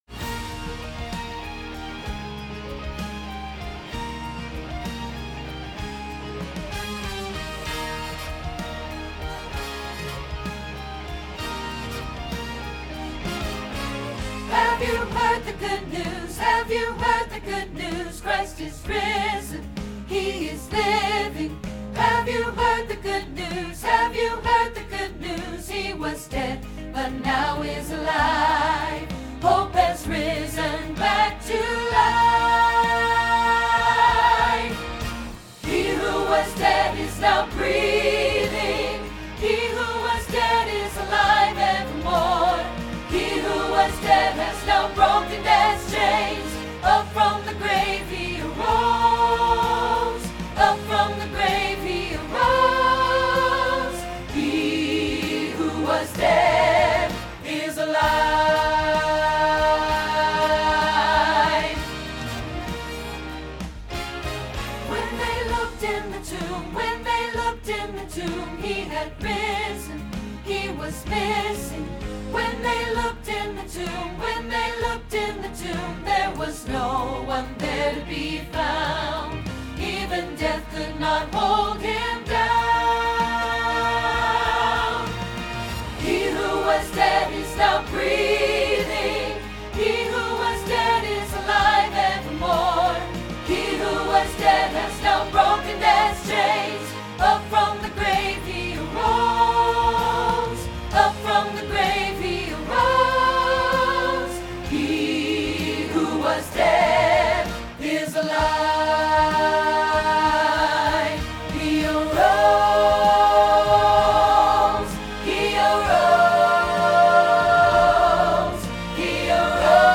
He Who Was Dead – Alto Hilltop Choir
He-Who-Was-Dead-Alto.mp3